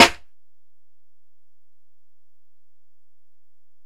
Snare (55).wav